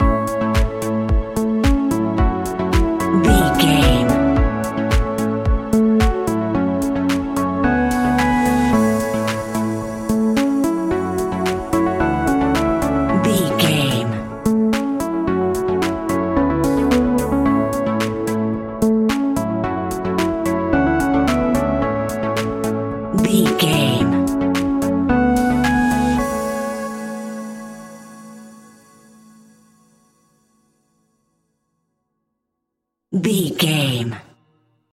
Ionian/Major
groovy
uplifting
energetic
synthesiser
drums
electric piano
electronic
synth leads
synth bass